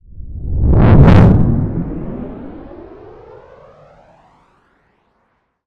sci-fi_vehicle_pass_01.wav